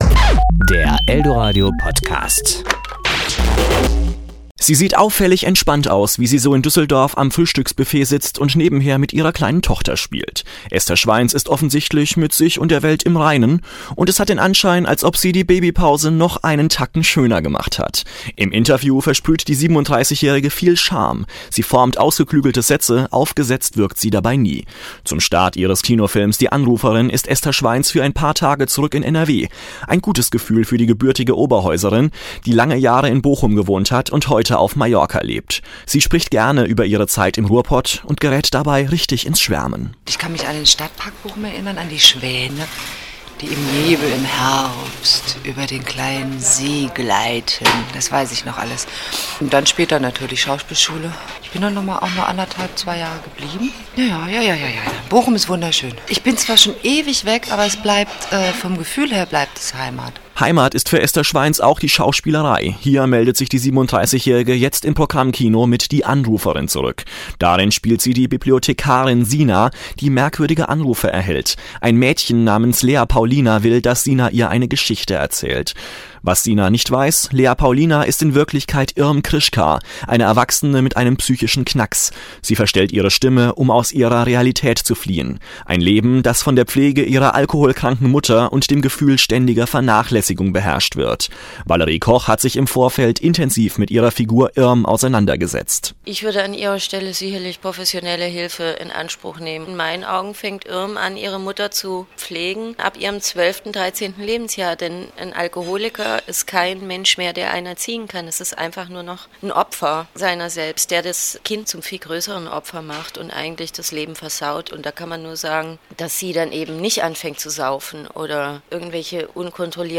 Esther Schweins im Interview / Kino-Rezension: Die Anruferin